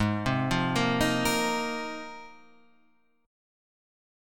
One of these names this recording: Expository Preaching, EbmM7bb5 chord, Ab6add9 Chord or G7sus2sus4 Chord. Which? Ab6add9 Chord